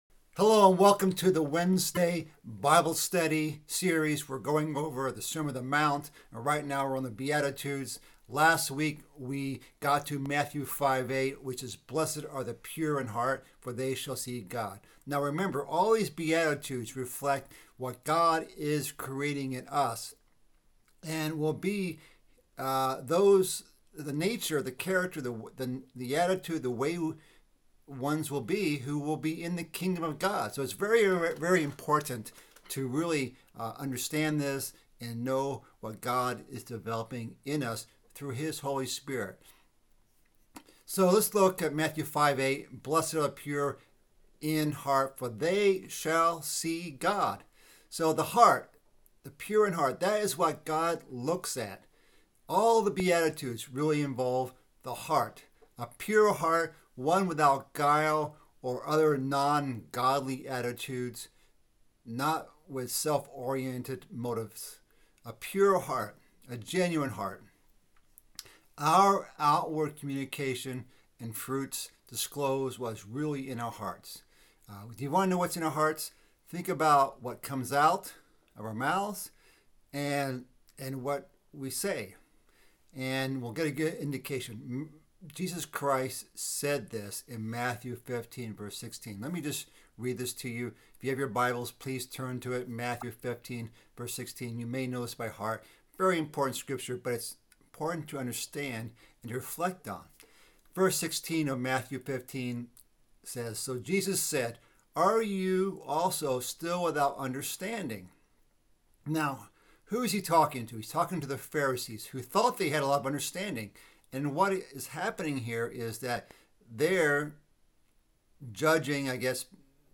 Bible Study - Sermon on the Mt. Part 3 - Matthew 5:8